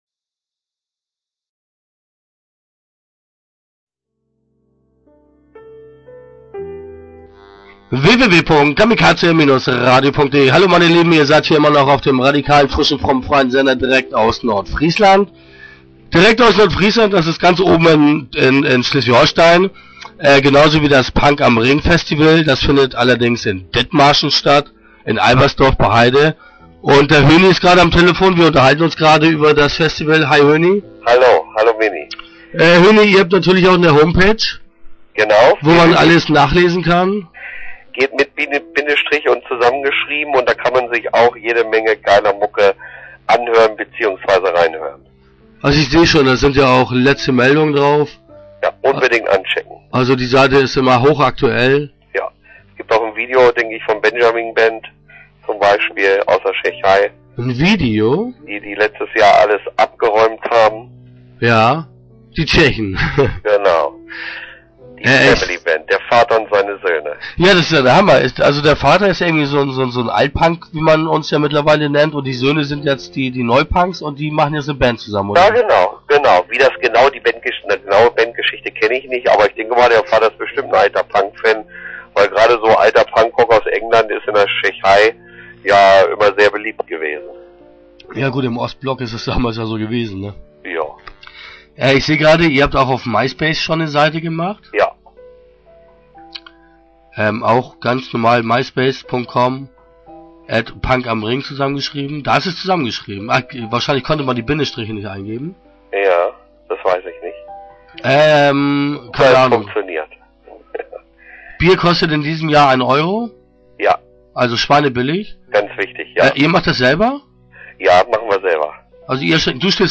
Start » Interviews » Punk am Ring